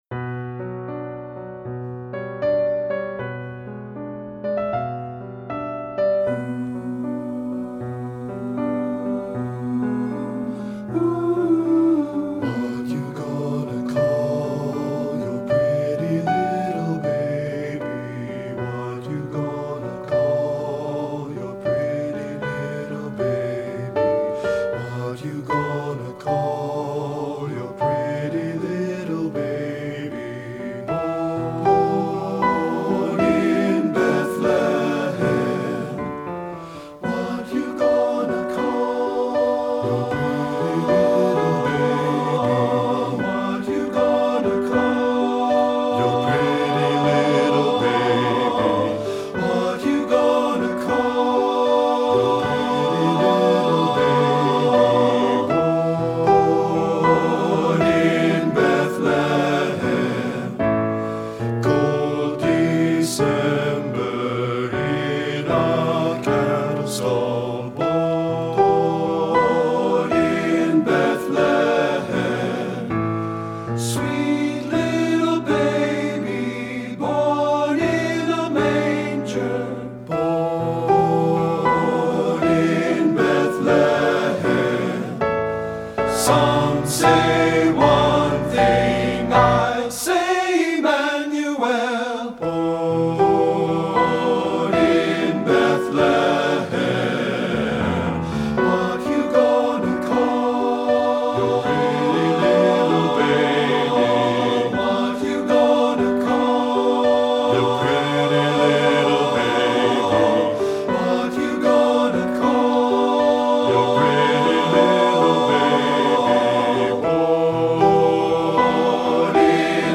Studio Recording
traditional Christmas spiritual
Ensemble: Tenor-Bass Chorus
Accompanied: Accompanied Chorus